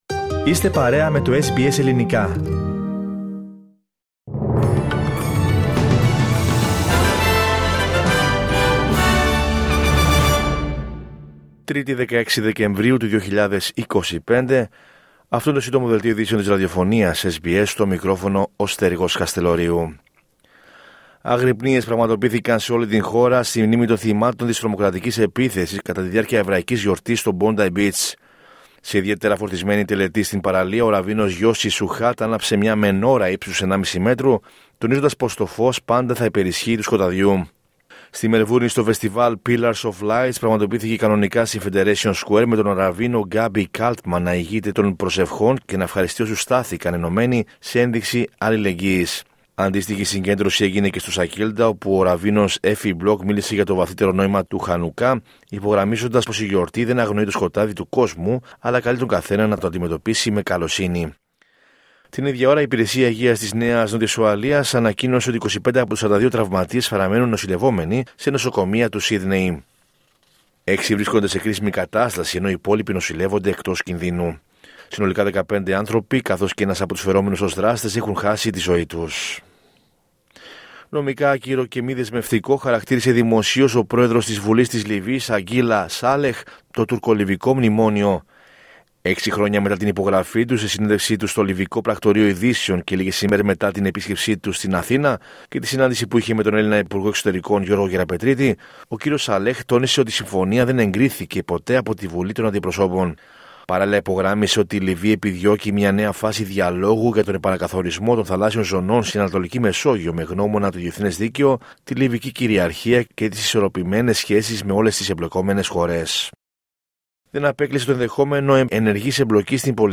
H επικαιρότητα έως αυτή την ώρα στην Αυστραλία, την Ελλάδα, την Κύπρο και τον κόσμο στο Σύντομο Δελτίο Ειδήσεων της Τρίτης 16 Δεκεμβρίου 2025.